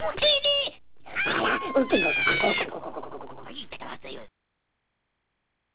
Jawas raptando a los androides: R2D2 y C3PO